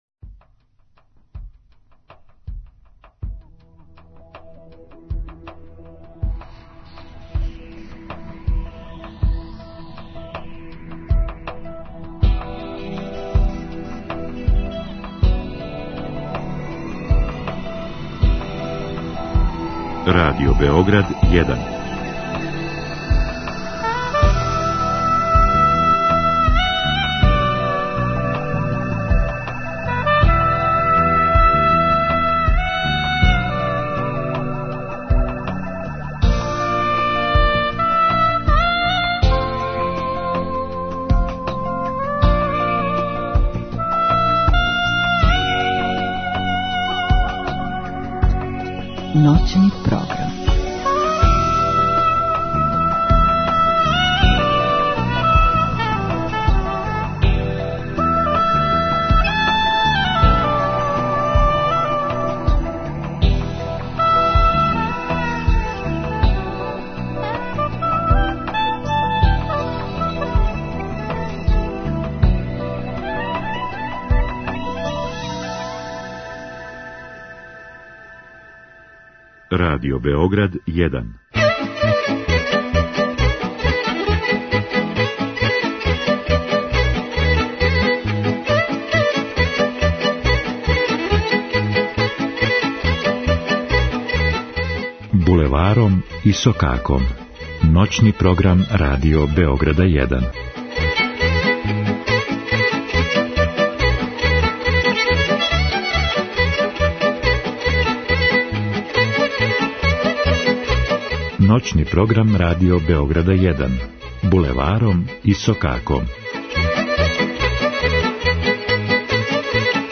У емисији можете слушати изворну, староградску и музику у духу традиције.